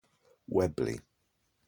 Weobley (/ˈwɛbli/